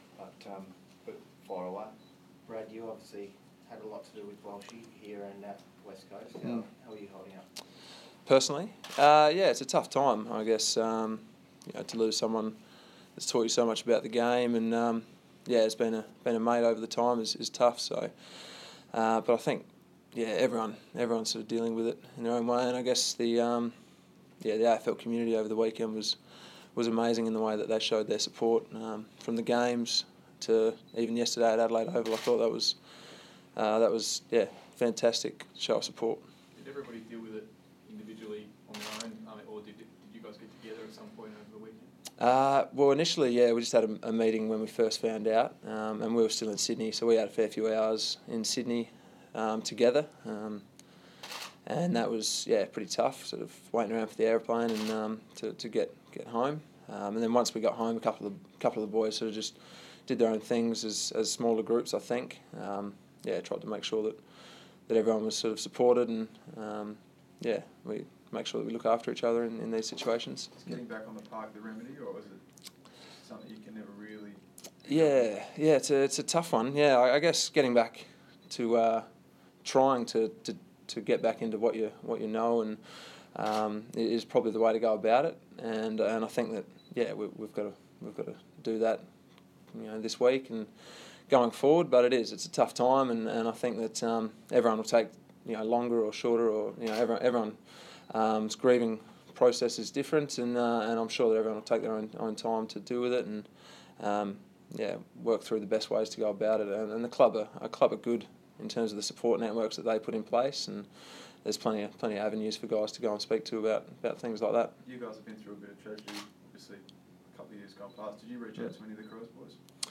Brad Ebert press conference - Monday 6 July, 2015
Vice captain Brad Ebert talks to media about how the players are dealing with the devastating loss of Phil Walsh and the Power's upcoming clash with Collingwood.